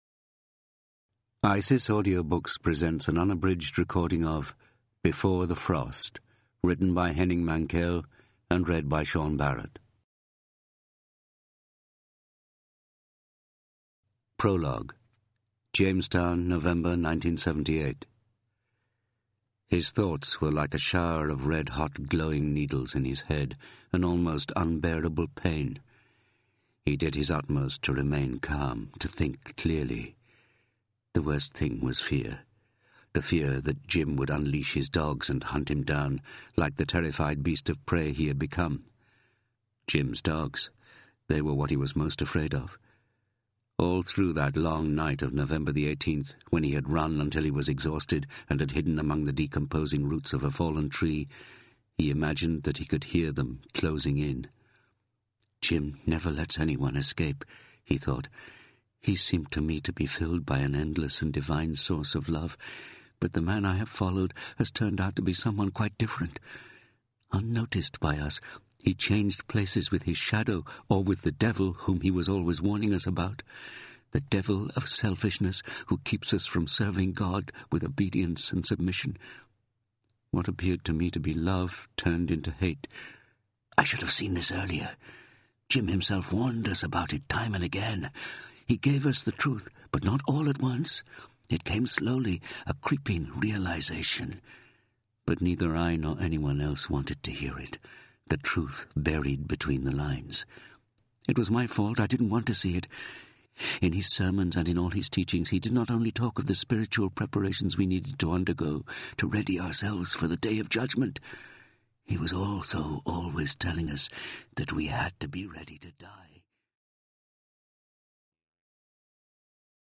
Talking books
By Genre Audiobooks